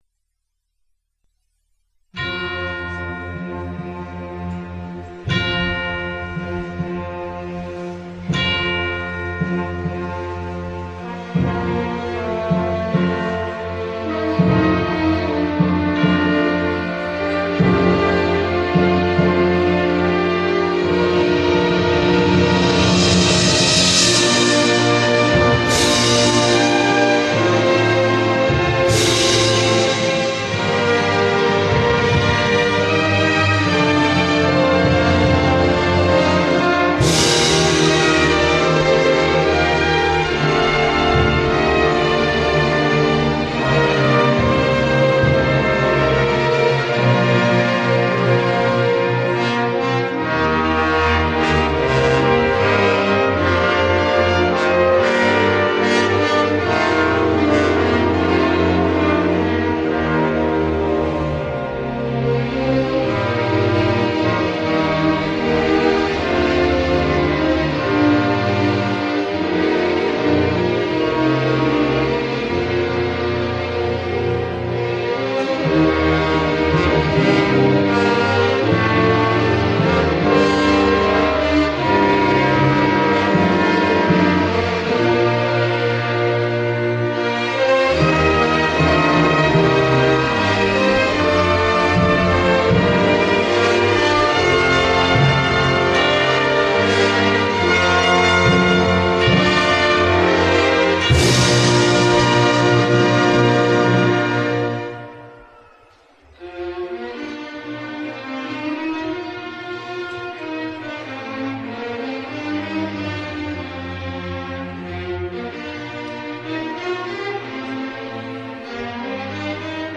Marimba